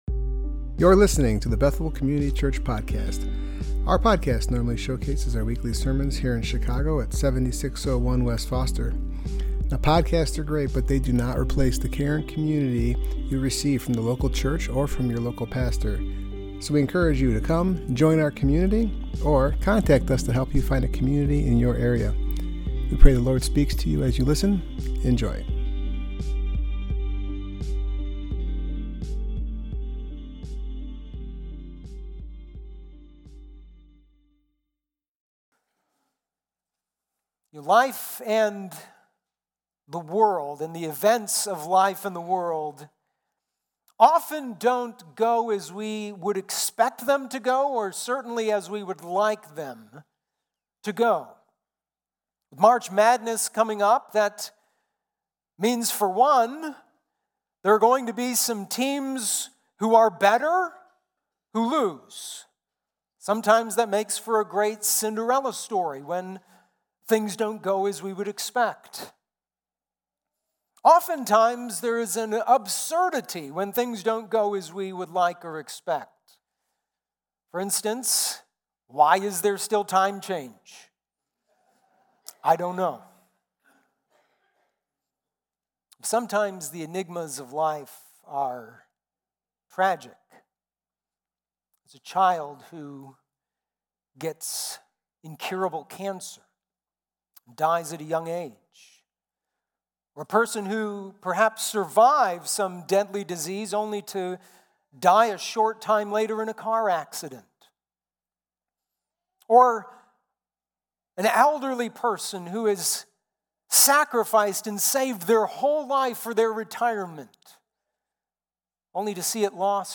Passage: Ecclesiastes 7:15-29 Service Type: Worship Gathering